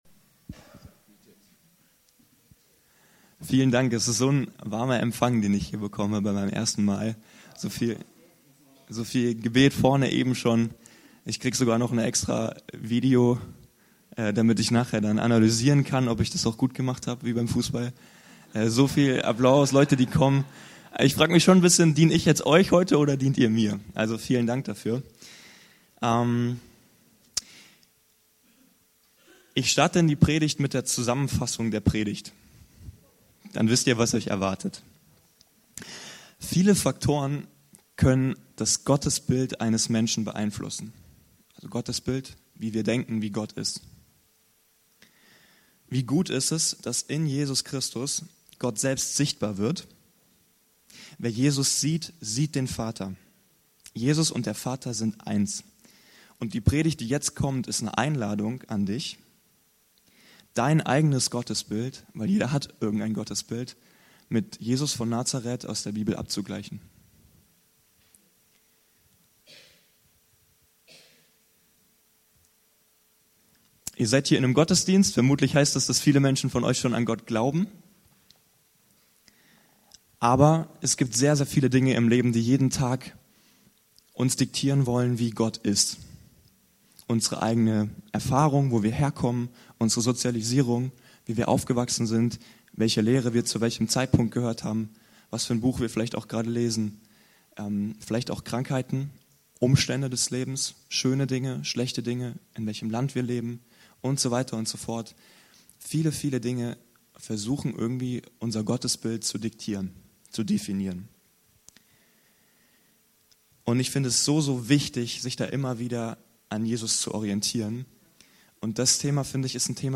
Diese Predigt ist eine Einladung, dein Gottesbild mit Jesus von Nazar...